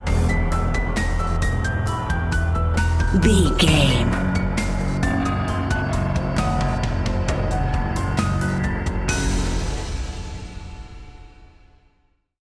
Aeolian/Minor
dark
eerie
ominous
suspense
drum machine
piano
synthesiser